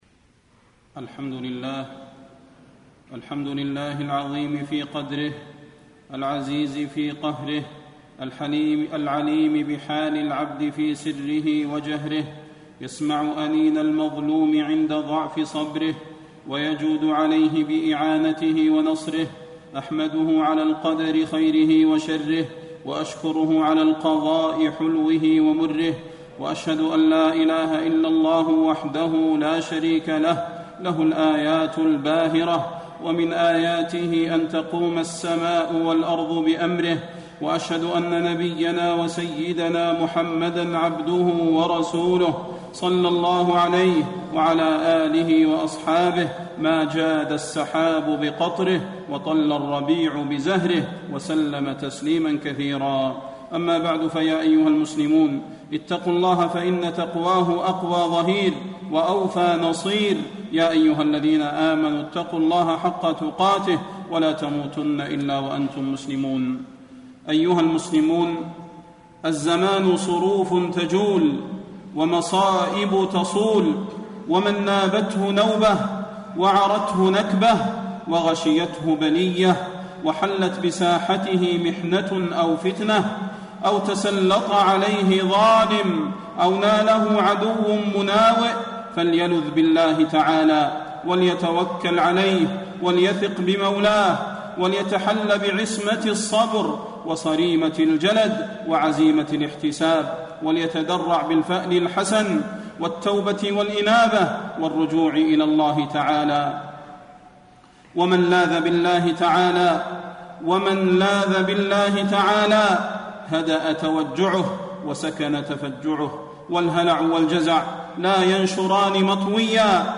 خطب الحرم المكي